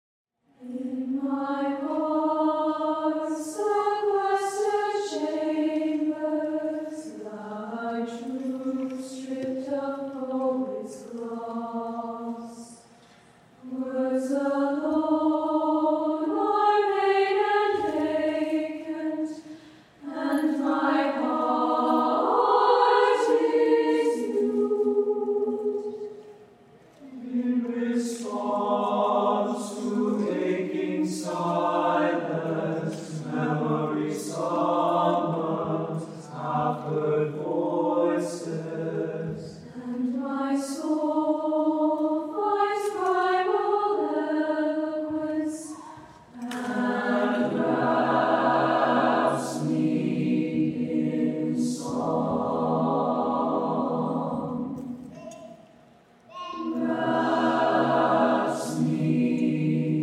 four curricular choirs and two a cappella ensembles
Sing With Heart: Spring Concert, 2019
With: Vocal Ensemble